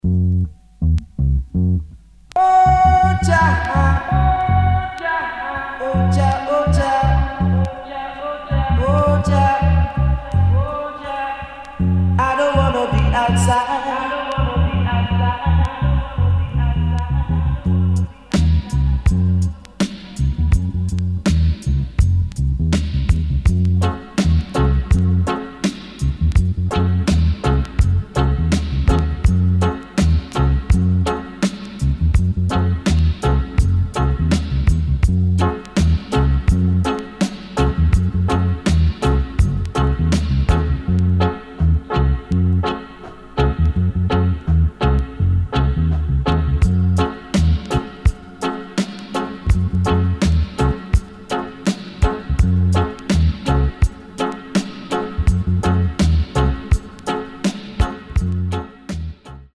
[ REGGAE | DUB ]